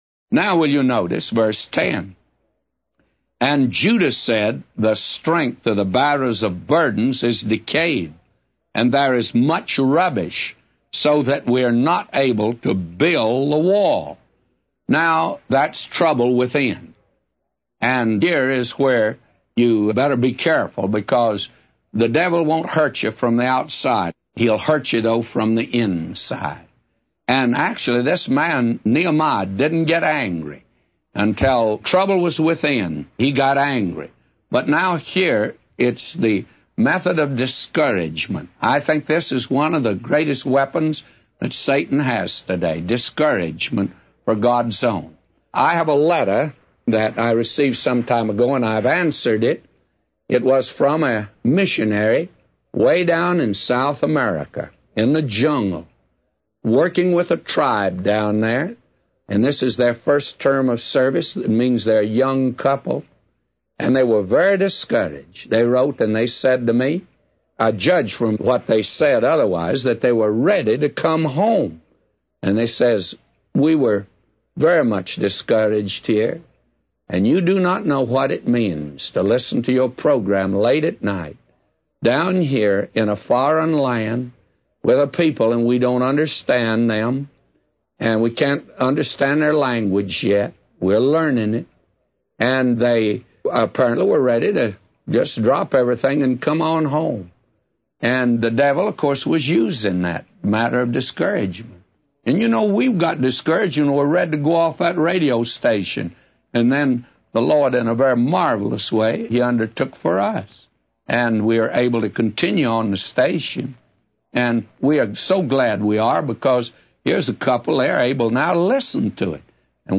A Commentary By J Vernon MCgee For Nehemiah 4:10-999